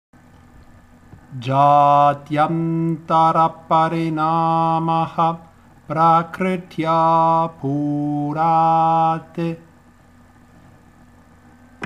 Kaivalya padah canto vedico – Yoga Saram Studio